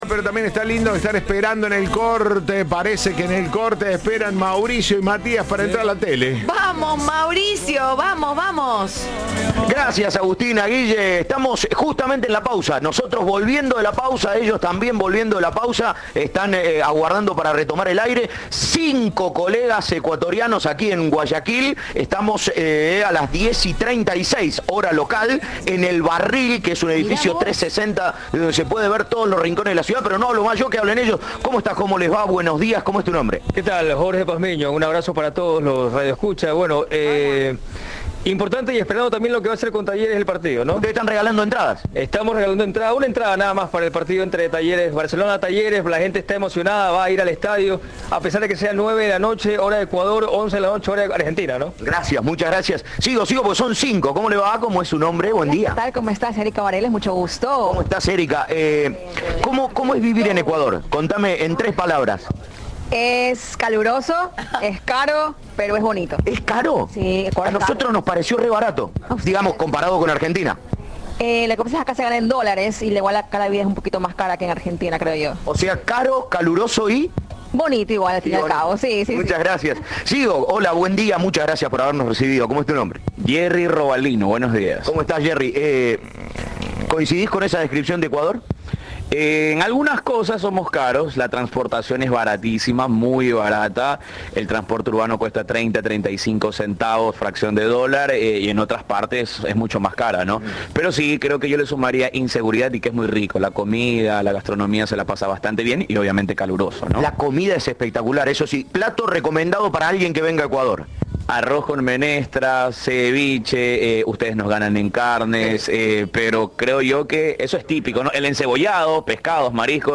en un programa por streaming de Ecuador.